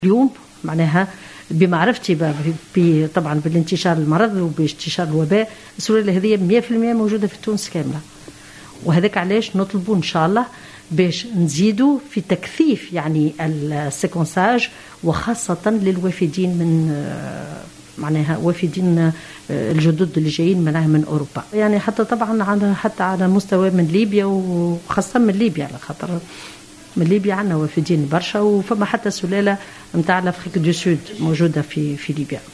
ودعت في مداخله لها، اليوم الخميس، على القناة الوطنية الأولى، إلى متابعة جميع الوافدين من الخارج، وخاصة من ليبيا التي تشهد انتشارا للسلالة الافريقية.